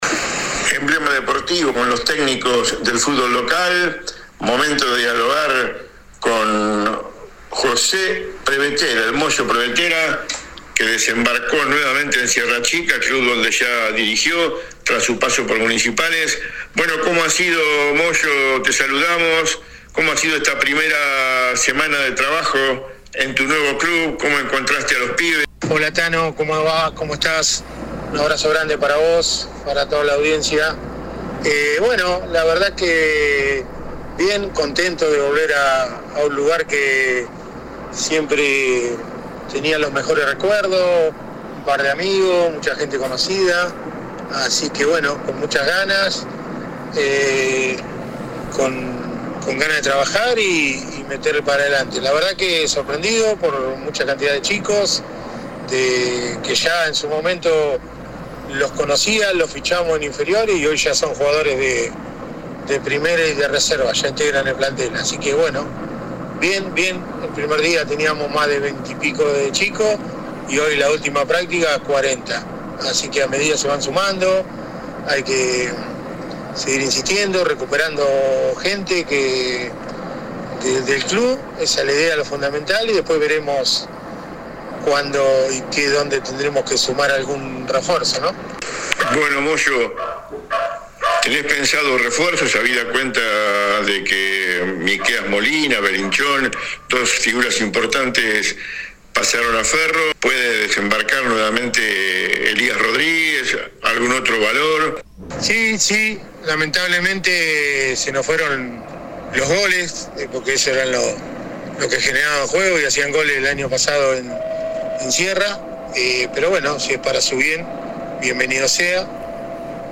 AUDIO DE LA ENTREVISTA https